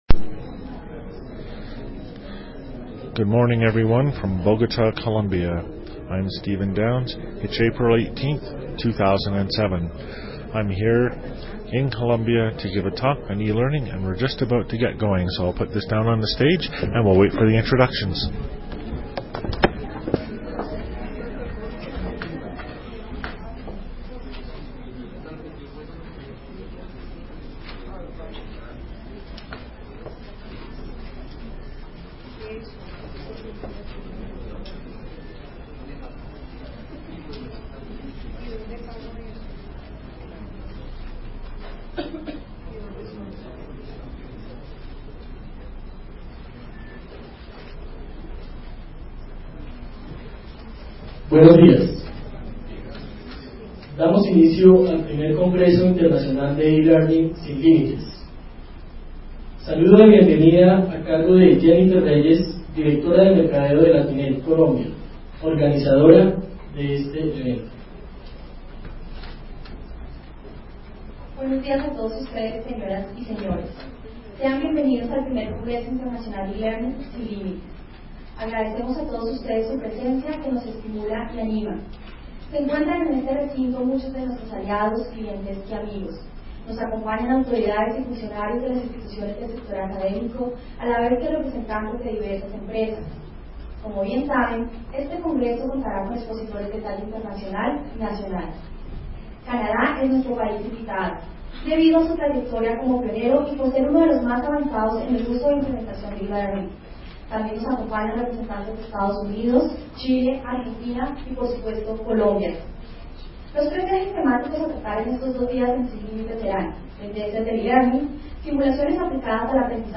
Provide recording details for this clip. Congreso Internacional de e-Learning, National University Telecommunications Network, Bogota, Colombia, Keynote